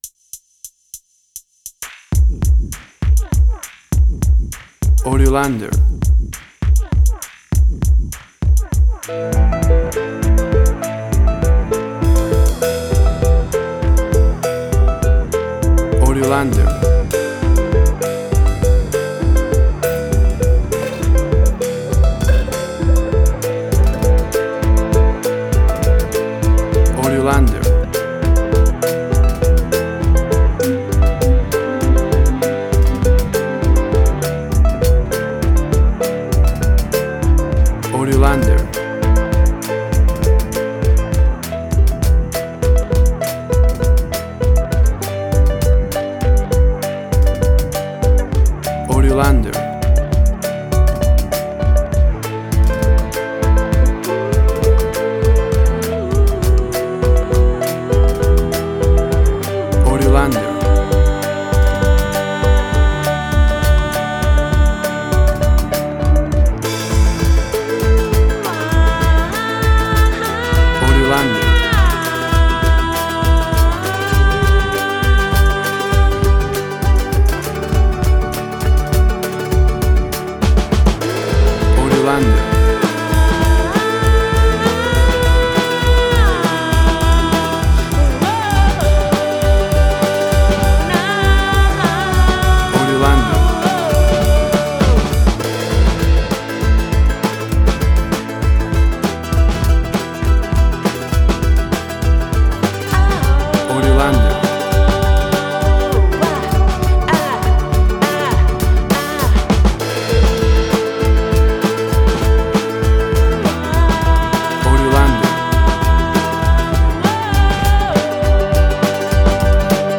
This song is an epic and ethnic mood.
Tempo (BPM): 100